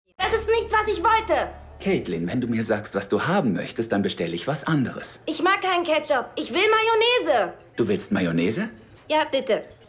dunkel, sonor, souverän, sehr variabel, markant
Synchron - Kinderstimme
Lip-Sync (Synchron), Children's Voice (Kinderstimme)